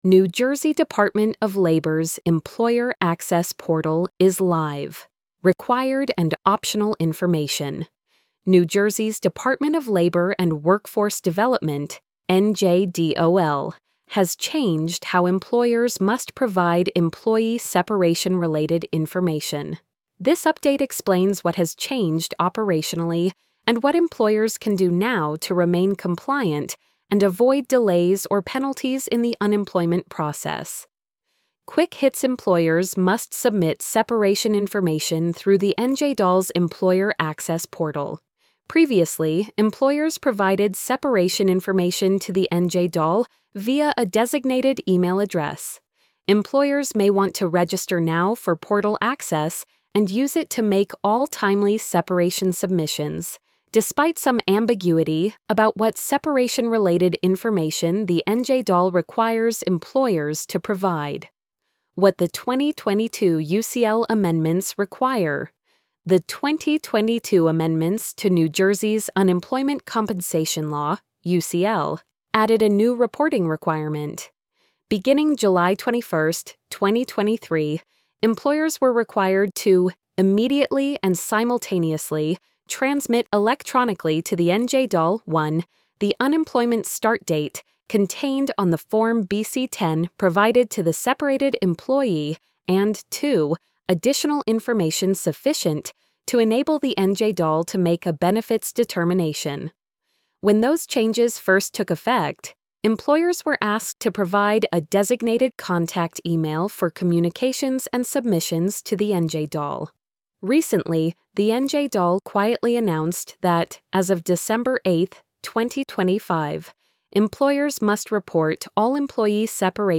new-jersey-department-of-labors-employer-access-portal-is-live-required-and-optional-information-tts.mp3